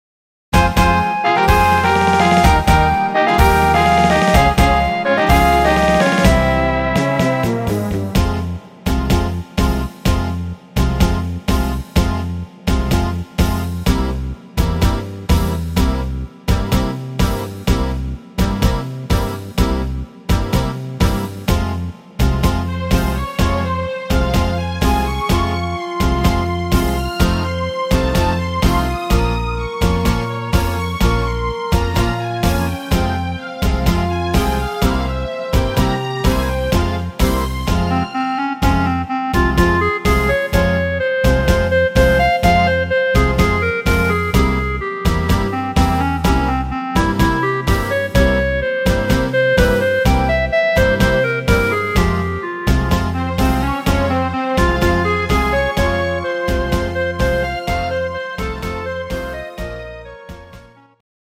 instr. Akkordeon